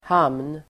Uttal: [ham:n]
hamn.mp3